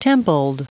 Prononciation du mot templed en anglais (fichier audio)
Prononciation du mot : templed